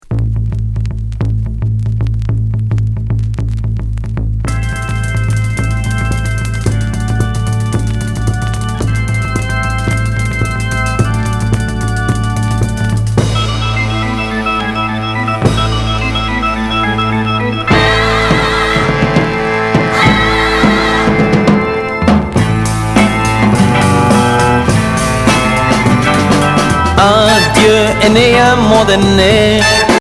Groove prog psychédélique Unique 45t retour à l'accueil